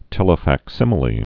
(tĕlə-făk-sĭmə-lē)